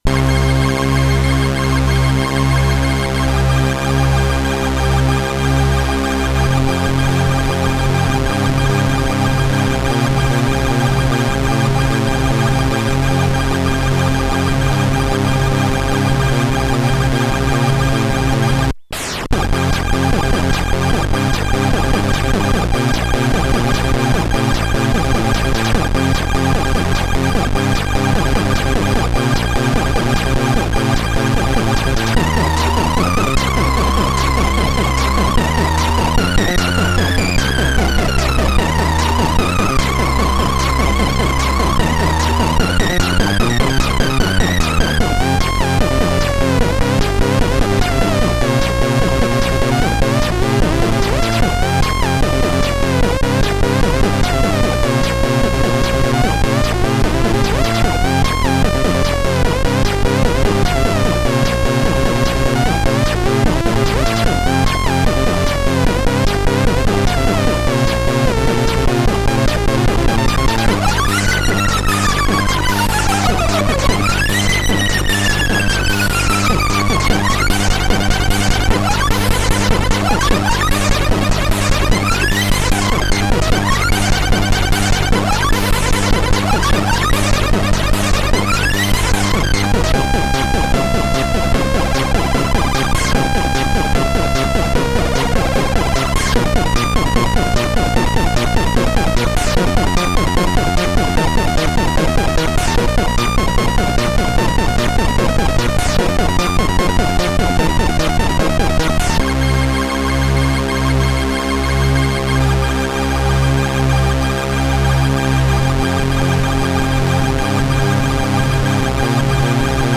c64 + cybertracker ... i'm new to c64 tracking so be nice